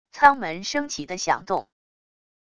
舱门升起的响动wav音频